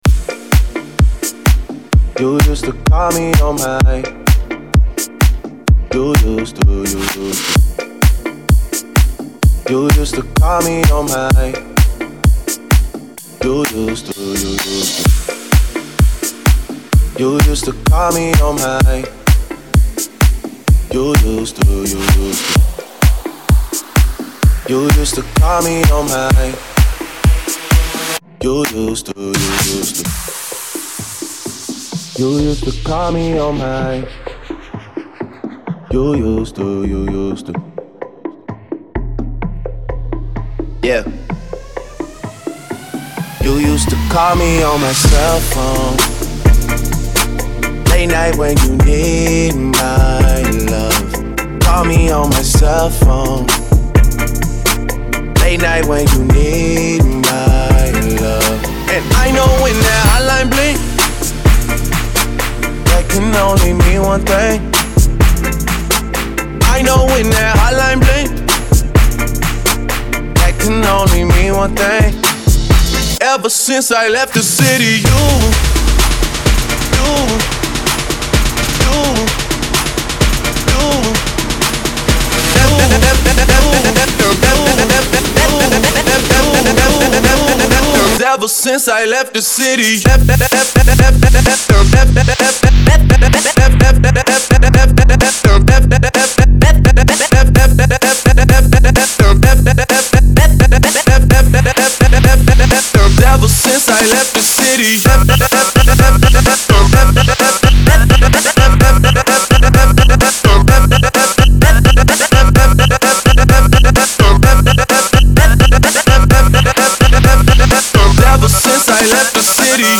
116 Bpm Genre: 70's Version: Clean BPM: 115 Time